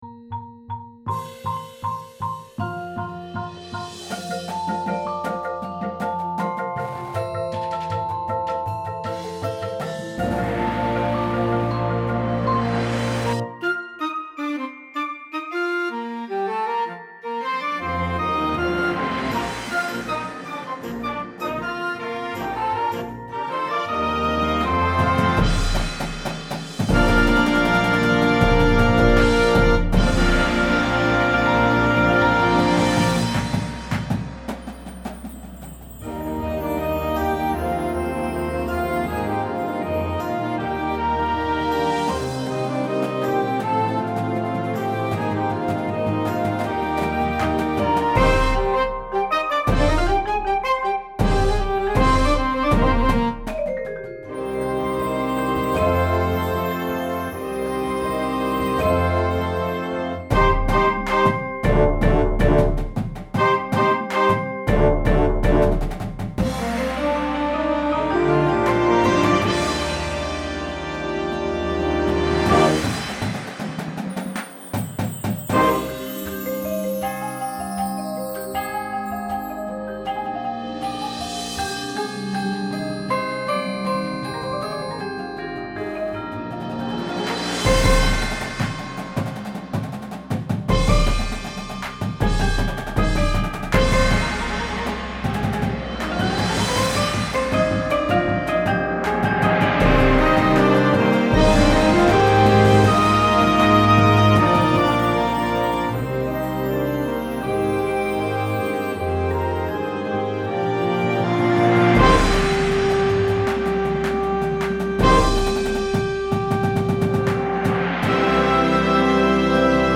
Instrumentation:
• Flute
• Alto Saxophone
• Trumpet 1, 2
• Trombone 1, 2
• Tuba
• Snare Drum
• Synthesizer
• Marimba 1
• Vibraphone 1